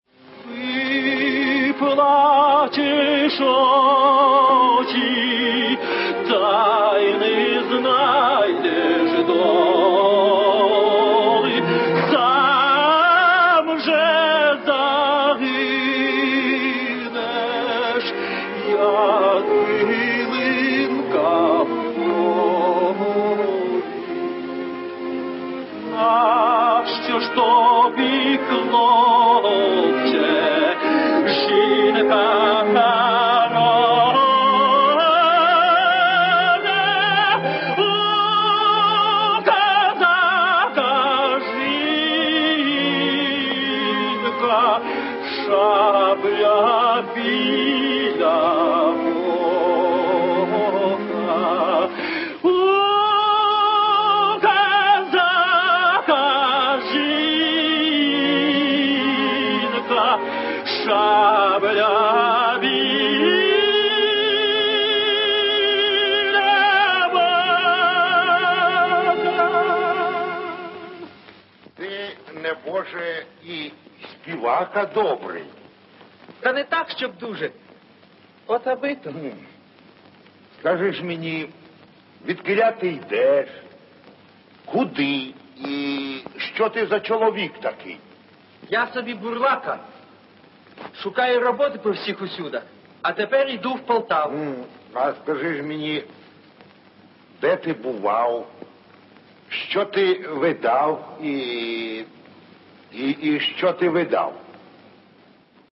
тенор